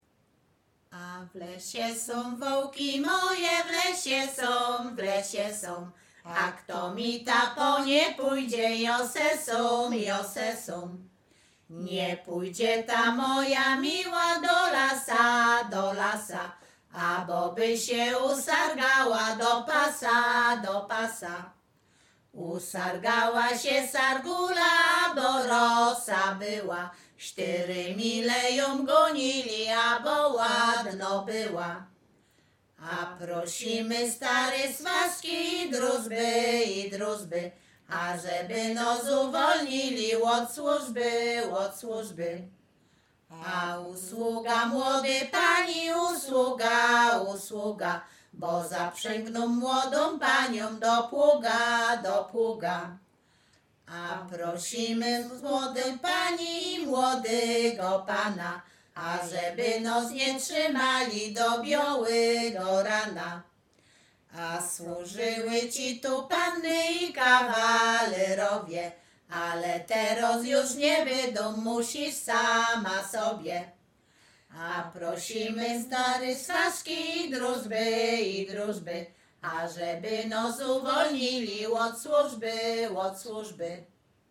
Przyśpiewki
przyśpiewki wesele weselne miłosne